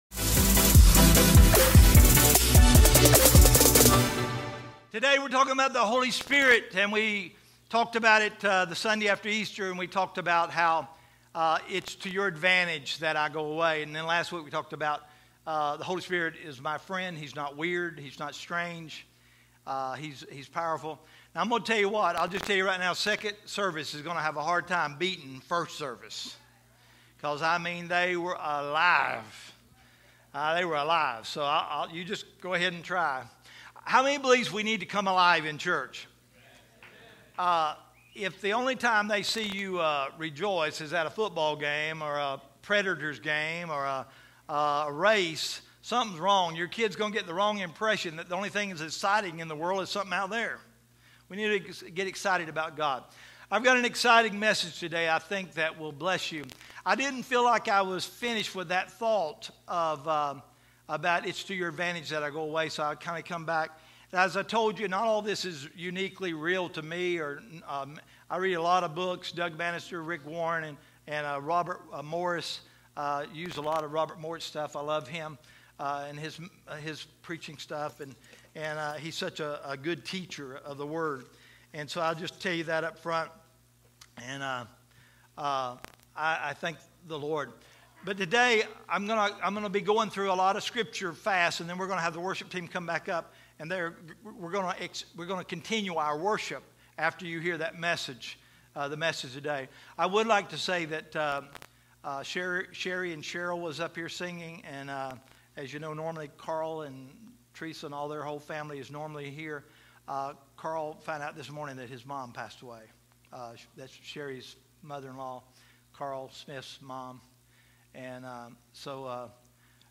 Listen to the next part of our sermon series “Holy Spirit”